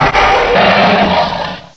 cry_not_hydreigon.aif